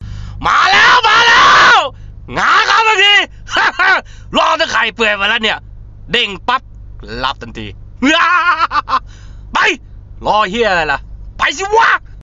เสียงเรียกเข้าตลก